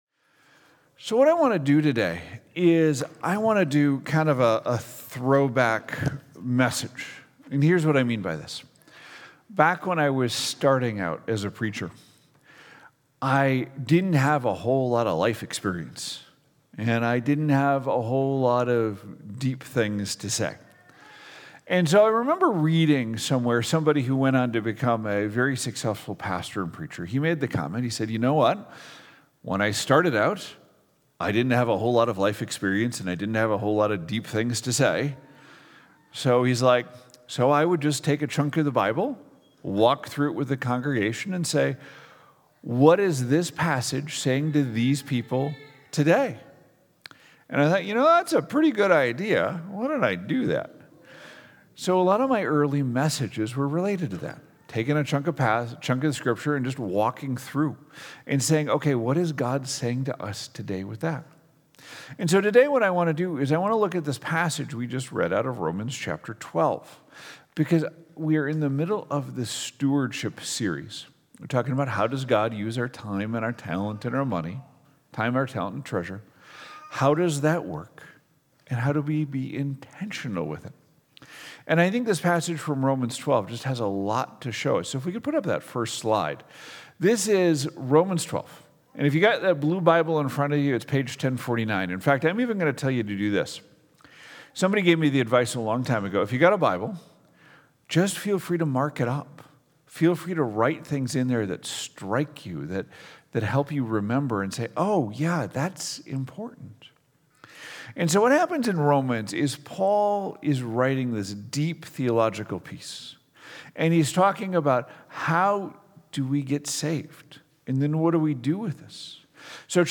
Full Hearts Sunday Morning Shepherd of the Hills Lutheran Church